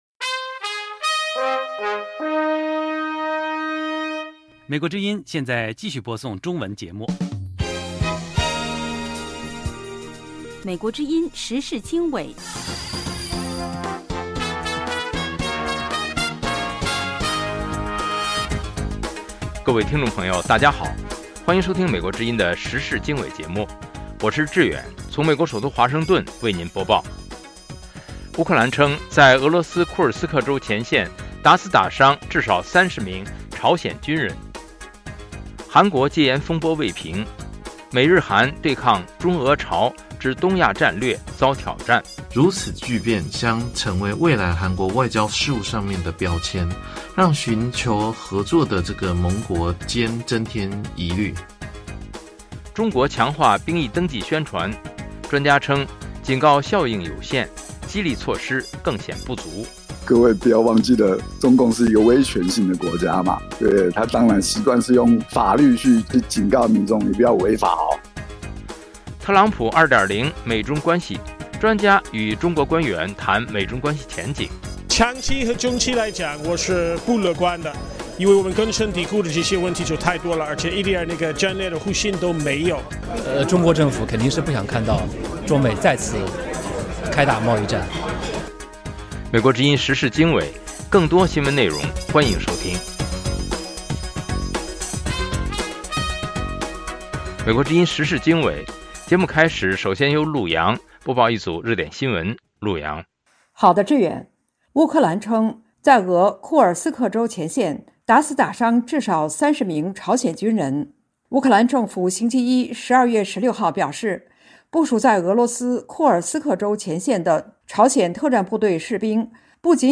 美国之音中文广播《时事经纬》重点报道美国、世界和中国、香港、台湾的新闻大事，内容包括美国之音驻世界各地记者的报道，其中有中文部记者和特约记者的采访报道，背景报道、世界报章杂志文章介绍以及新闻评论等等。